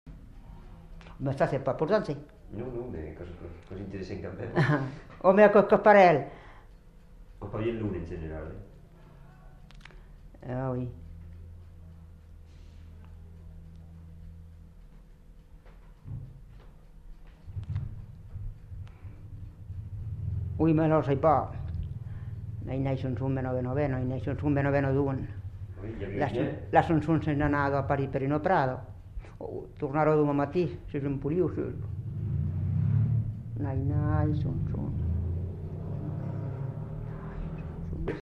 Aire culturelle : Haut-Agenais
Lieu : Castillonnès
Genre : chant
Type de voix : voix de femme
Production du son : parlé
Classification : som-soms, nénies